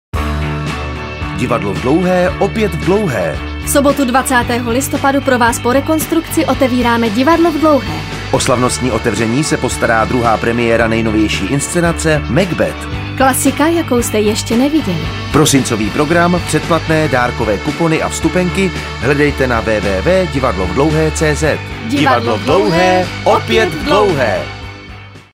Nové spoty Divadla v Dlouhé
Poslechněte si nové "macbethovské" spoty, které máte zároveň možnost zaslechnout v těchto dnech v rádiích!